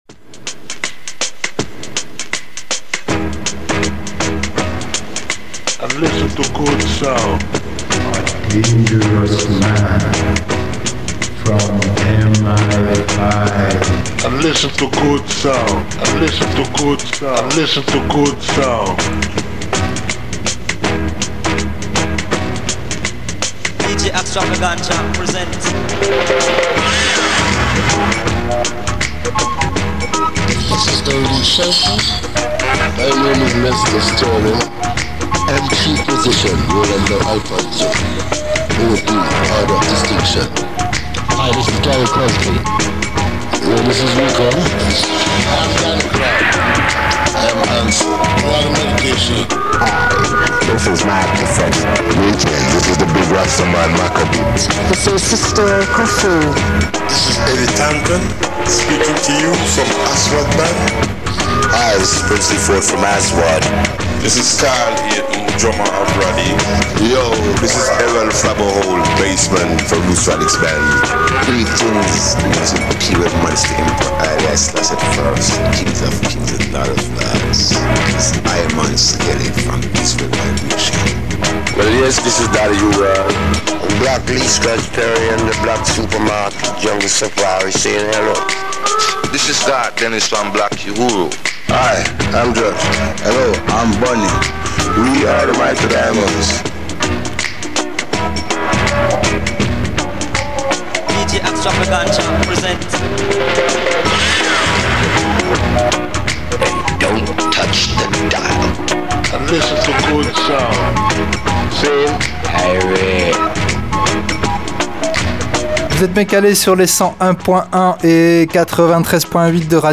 BLACK SUPERMARKET S22 #09 | Interview de JIM MURPLE MÉMORIAL – 23.10.2019